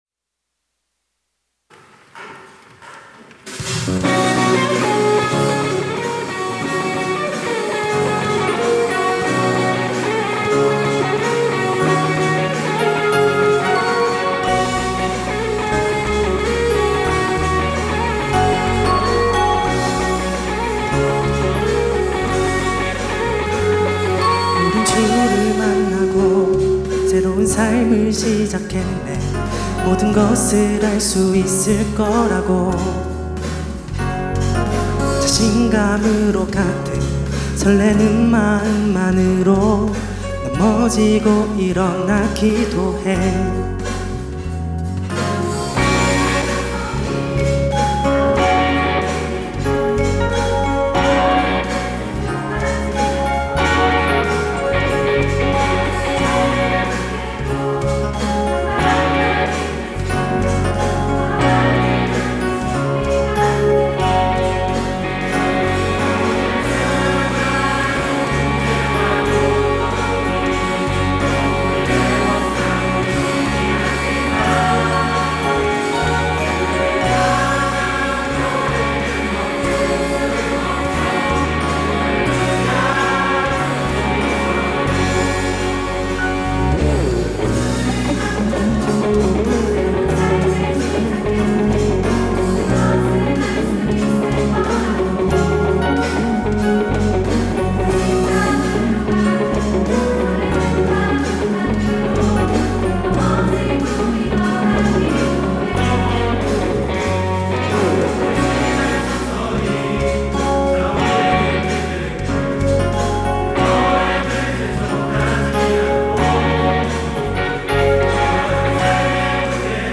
특송과 특주 - 우린 주를 만나고
청년부연합 찬양대